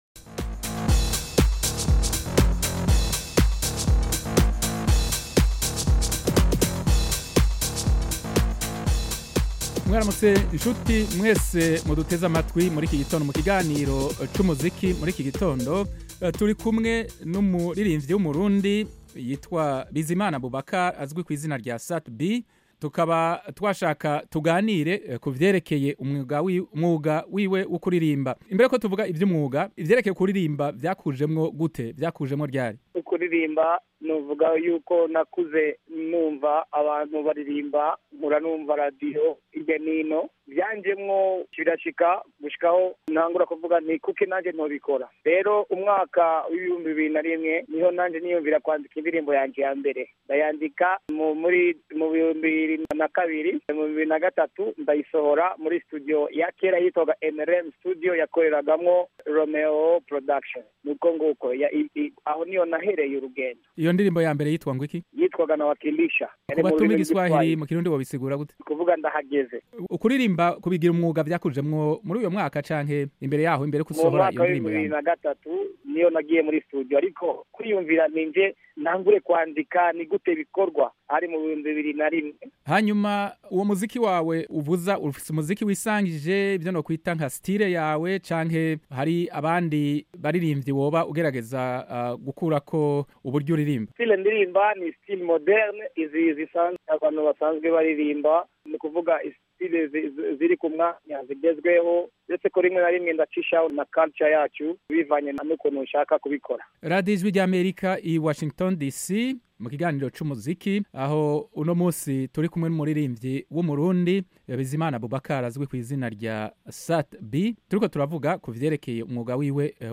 Ikiganiro n'umuririmvyi SAT-B kw'Ijwi rya Amerika